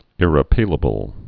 (ĭrĭ-pēlə-bəl)